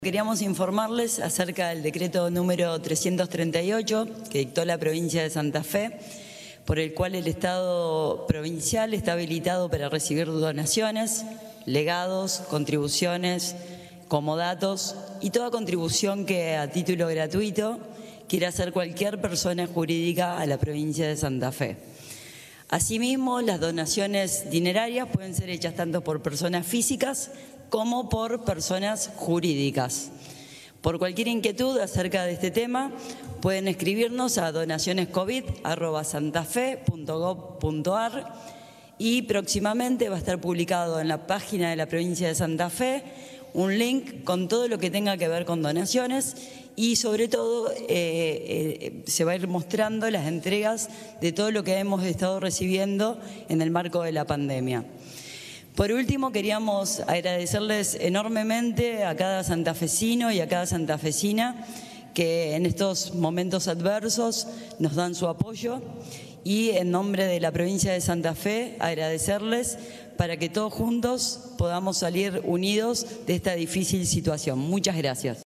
En conferencia de prensa, la directora provincial del Gobierno de Santa Fe en la sede Rosario, Marianela Blangini, informó acerca del decreto 338/20 firmado el 11 de abril pasado por el gobernador Omar Perotti.
Marianela Blangini - directora provincial del Gobierno de Santa Fe sede Rosario